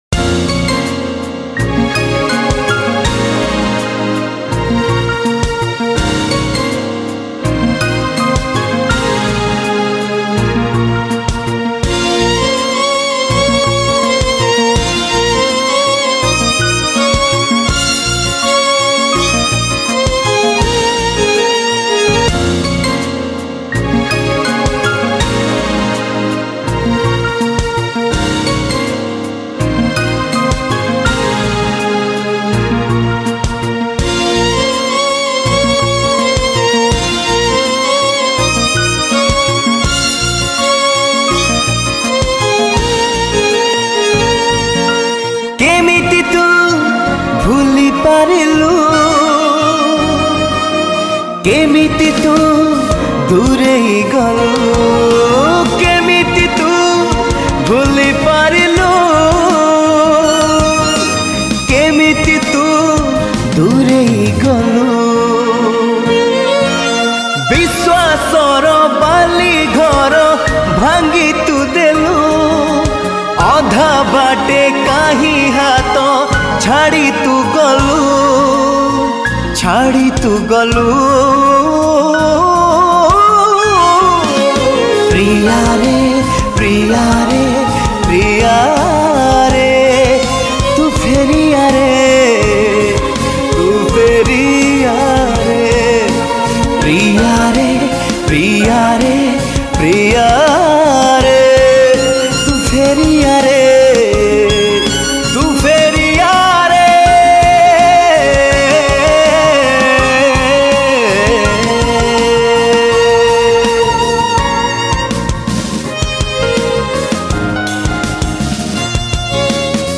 Beutiful Sad Romantic Song